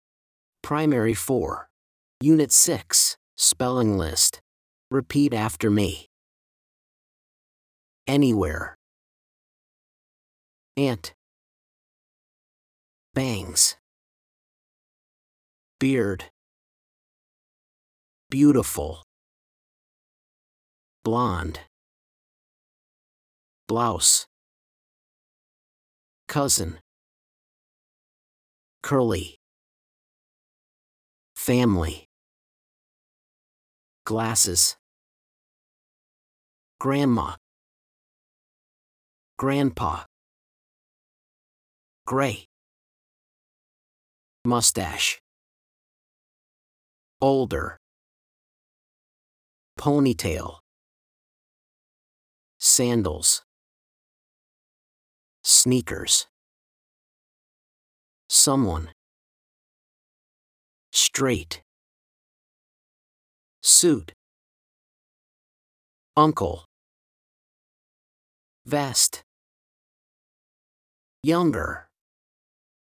blankThese are the words on the spelling list. Listen and repeat after the teacher: